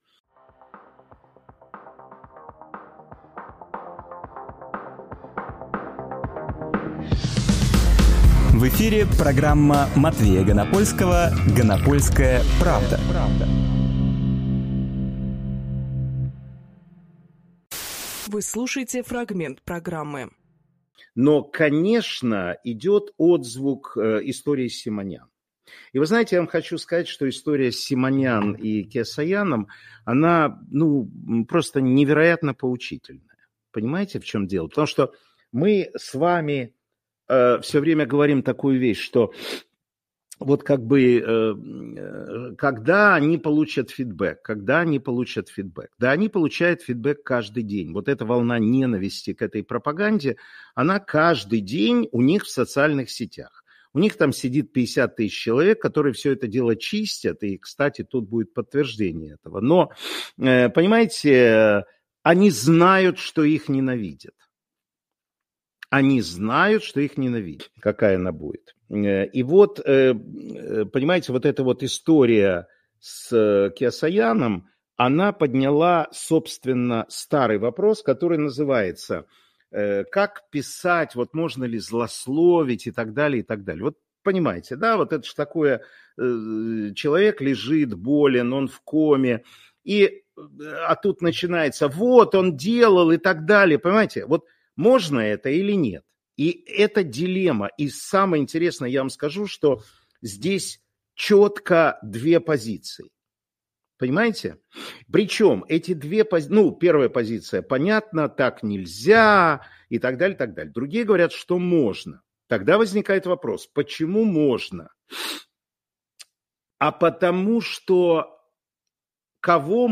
Фрагмент эфира от 11.01.25